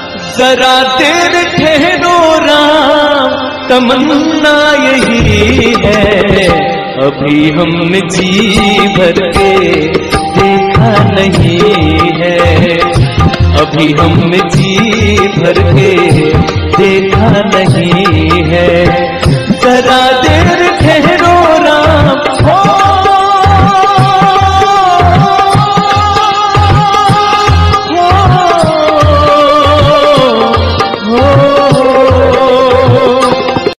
soulful Shri Ram bhajan ringtone